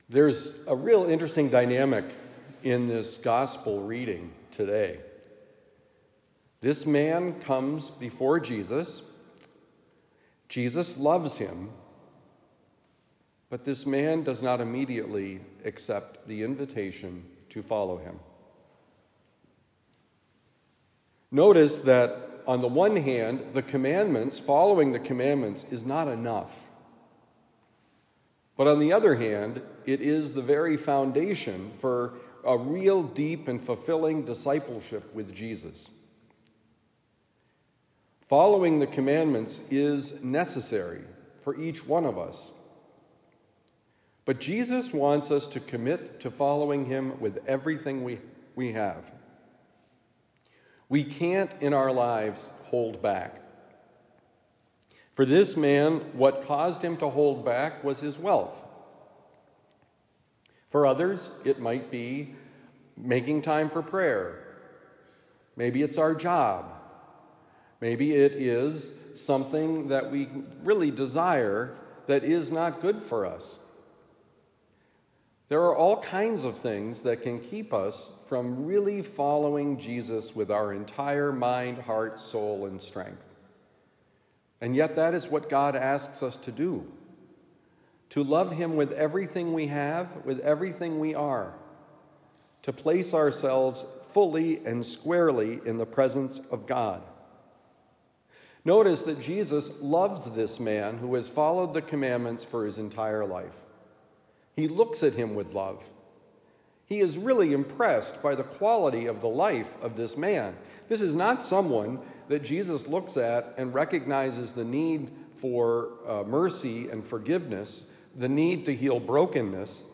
Jesus Loves Him: Homily for Monday, May 27, 2024